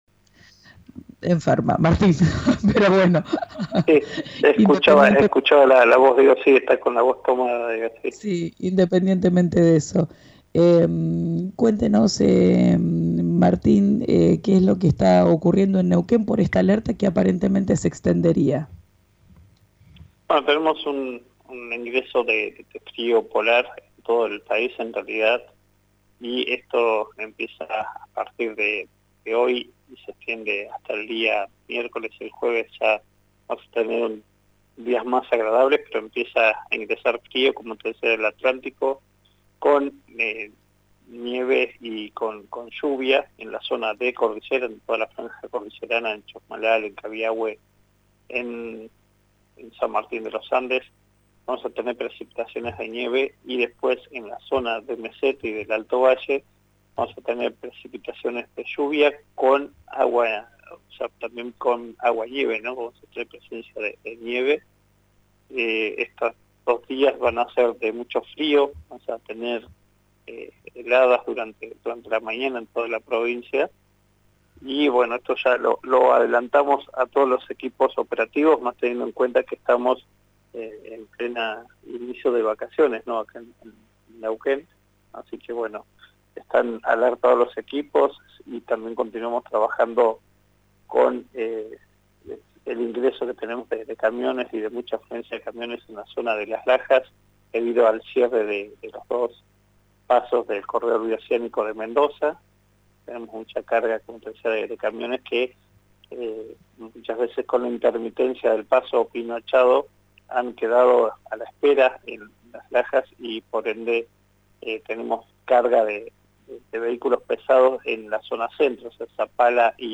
Escuchá al subsecretario de Defensa Civil y Protección Ciudadana, Martín Giusti, en RÍO NEGRO RADIO: